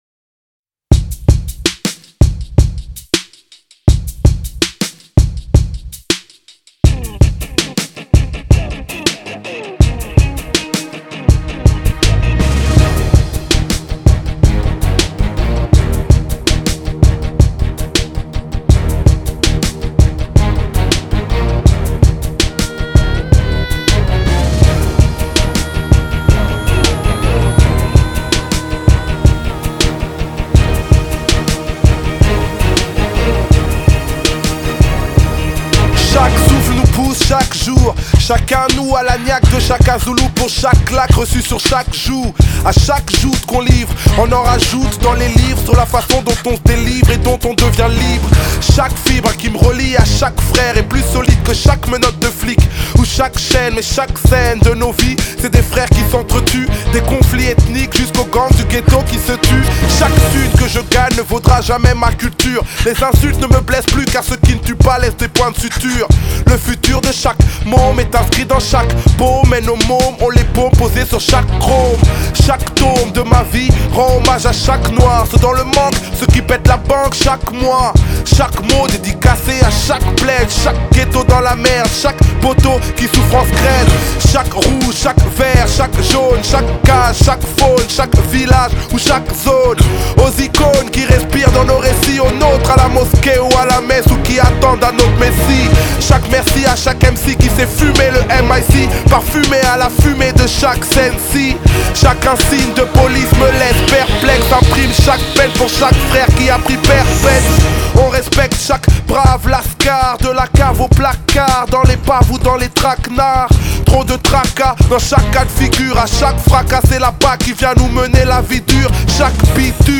Genre: French Rap